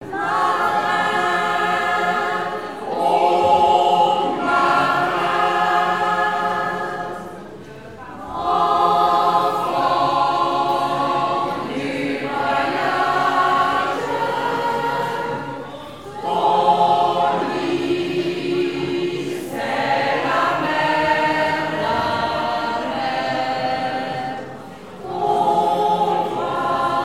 chorale
Pièce musicale inédite